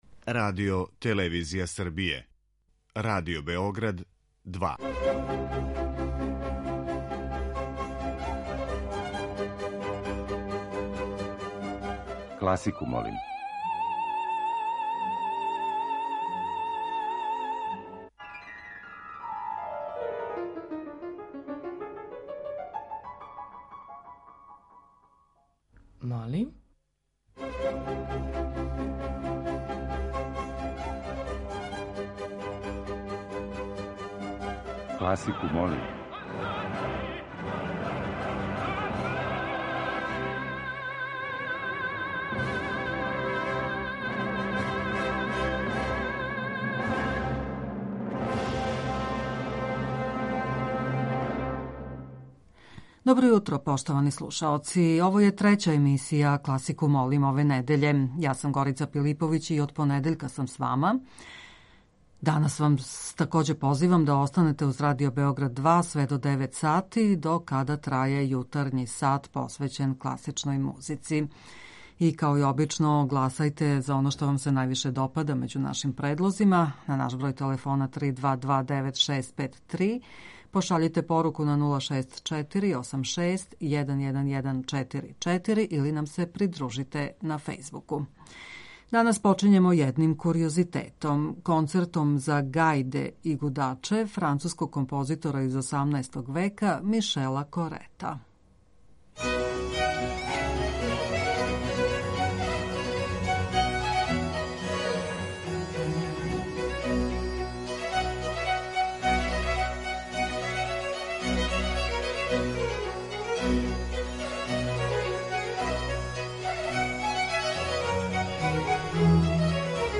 Избор за топ-листу класичне музике Радио Београда 2
Стилски и жанровски разноврсни циклус